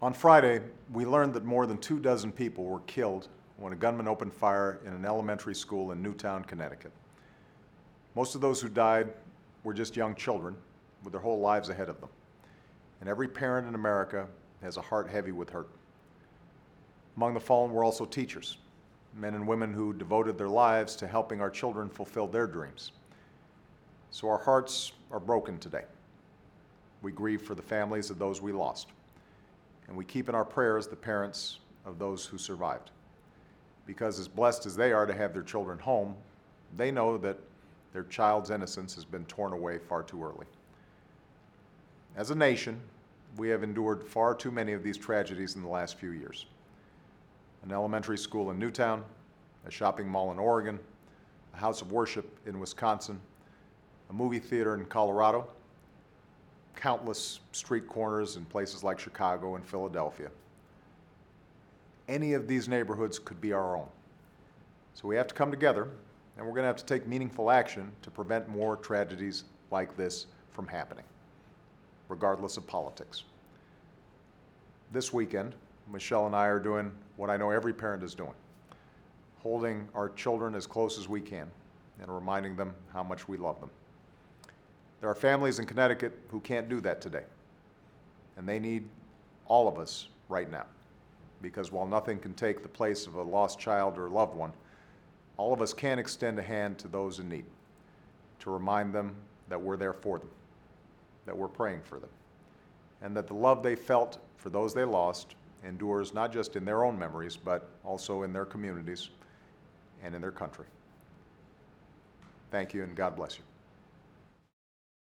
The President says the nation’s thoughts and prayers are with those who lost a loved one during Friday’s tragic shooting in Newtown, CT.